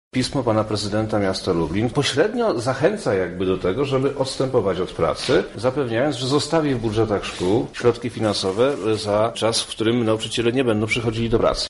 Wojewoda